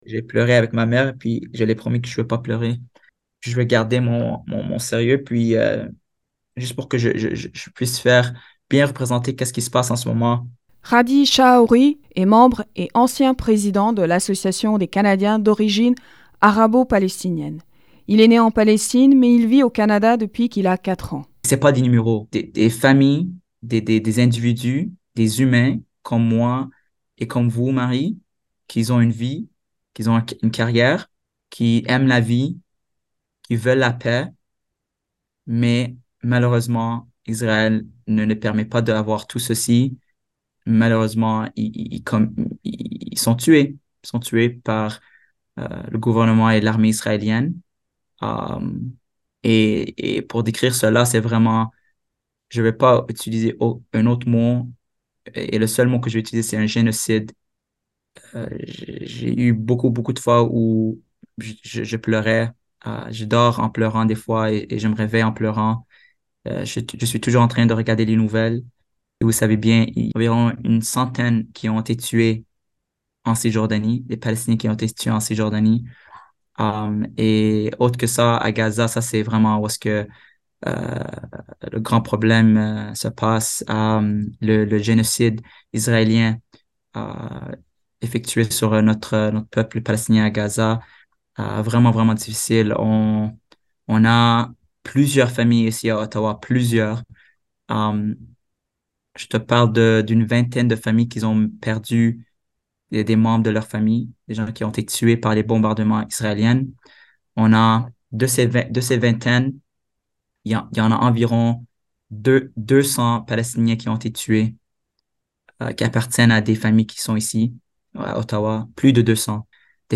Témoignage du sentiment des Palestiniens qui vivent au Canada, reportage